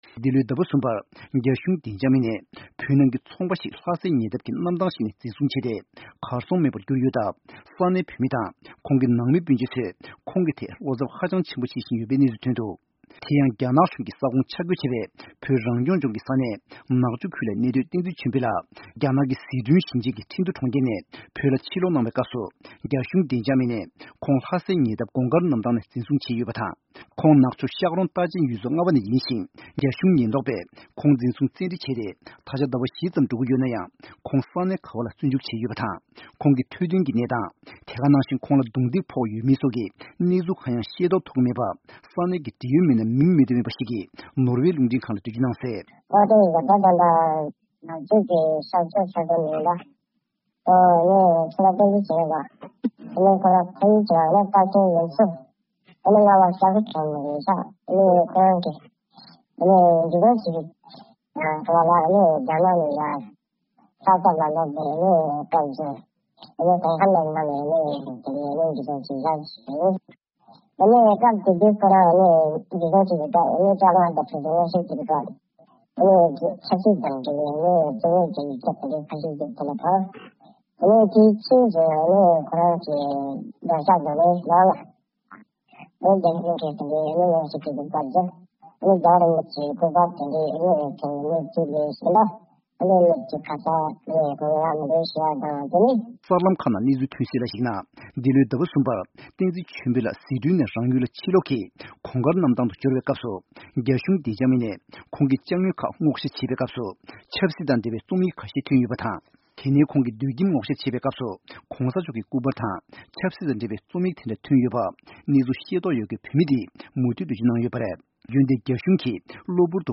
གནས་ཚུལ་སྙན་སྒྲོན་ཞུས་པ་ཞིག་གསན་གྱི་རེད།